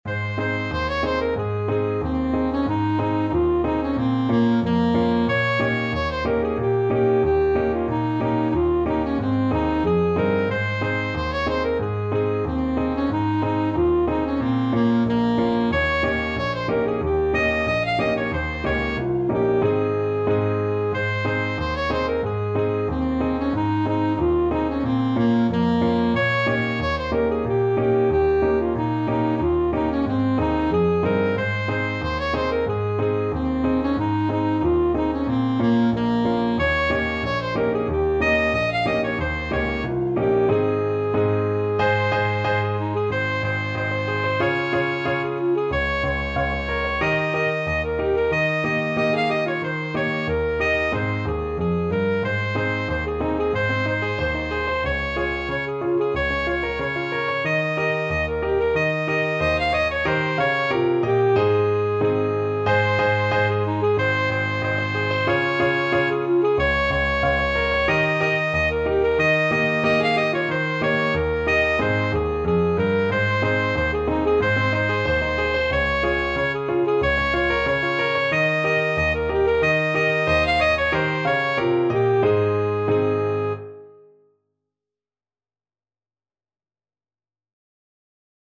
Alto Saxophone
Traditional Music of unknown author.
2/4 (View more 2/4 Music)
Moderato =c.92